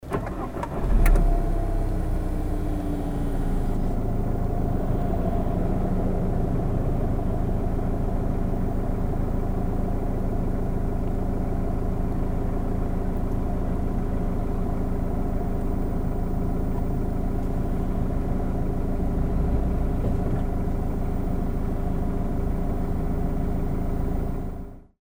やはり、カタカタした独特な音が車内にも伝わってくる所ですね。
実際に車内の運転席にて、騒音を収録してみました。
ディーゼルデミオ（温間時のアイドリング～空ふかし）
約5～6dB（デシベル）騒音が大きい事が分かりました。
d_demio_idling.mp3